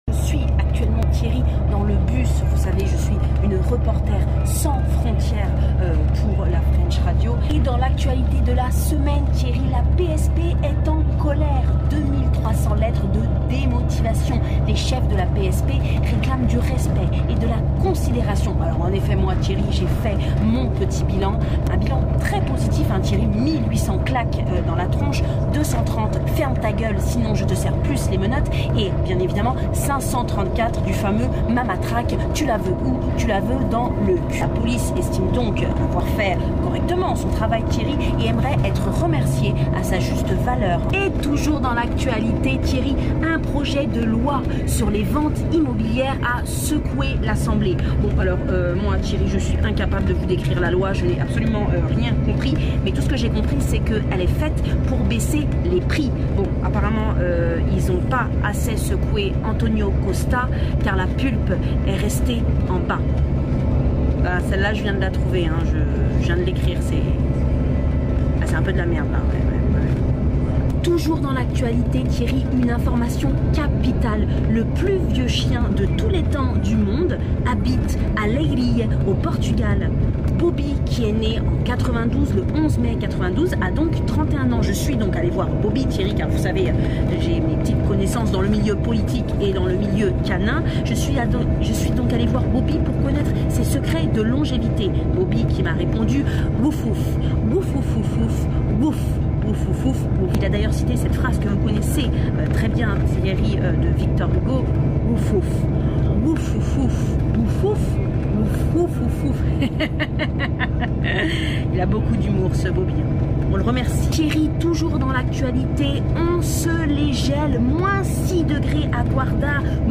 revue de presse décalée